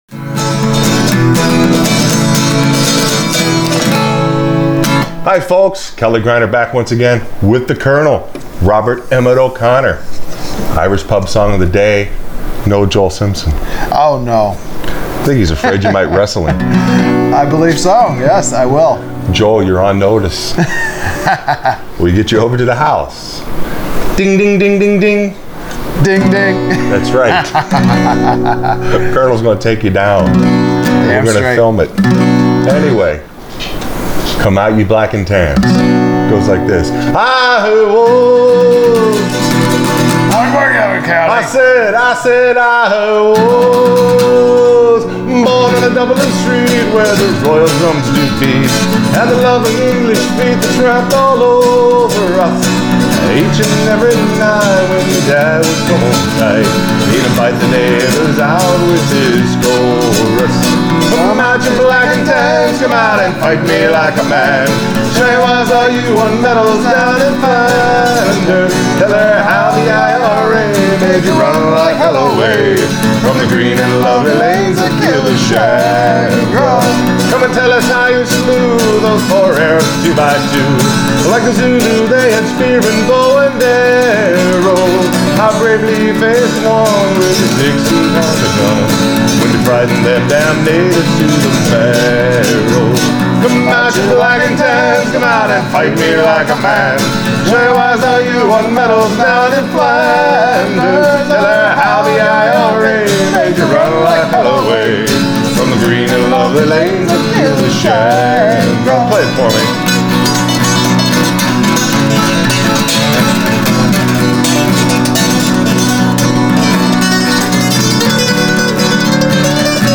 Irish Pub Song Of The Day – Come Out Ye Black And Tans – Accompaniment for Frailing Banjo
It’s a fun song and playing out of a minor key can be great!